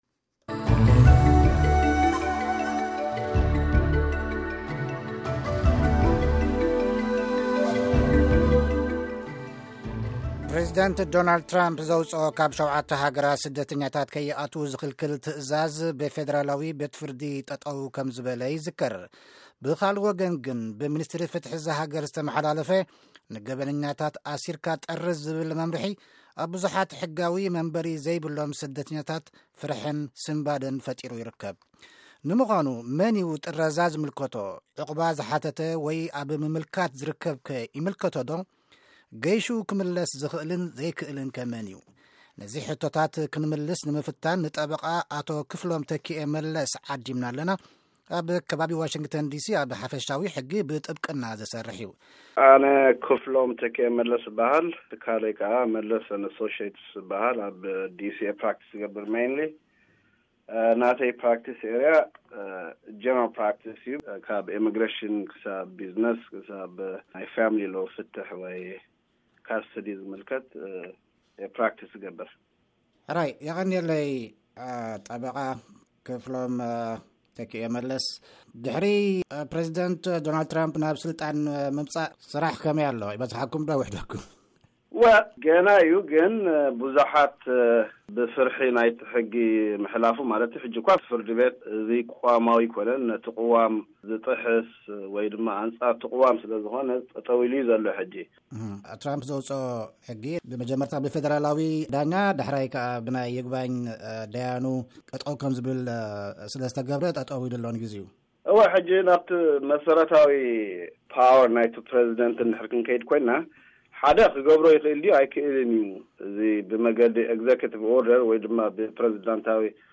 ቃለ-መጠይቕ: ሕጊ-ኢምግረሽን-ኣሜሪካ ንኤርትራውያንን ኢትዮጵያውያንን ከመይ ይጸልዎም?